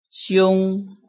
拼音查詢：【海陸腔】siung ~請點選不同聲調拼音聽聽看!(例字漢字部分屬參考性質)